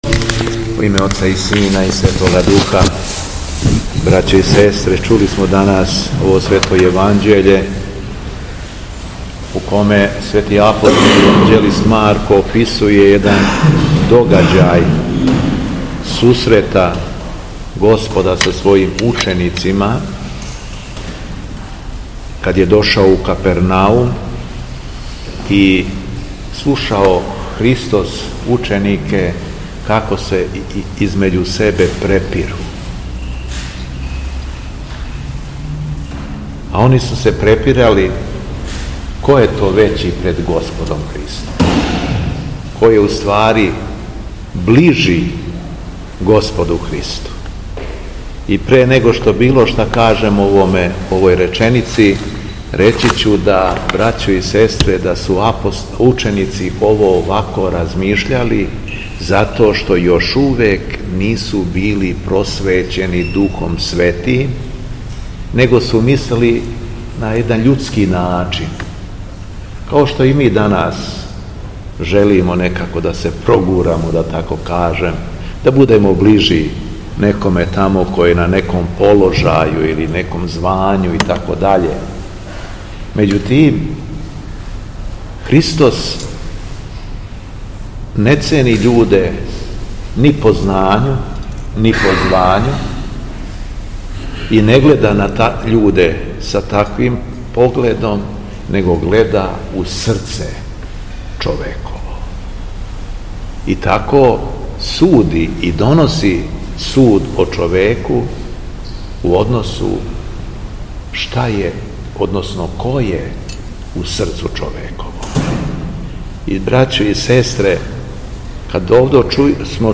Беседа Његовог Преосвештенства Епископа шумадијског г. Јована
После прочитаног Јеванђеља, Епископ се обратио сабраном народу: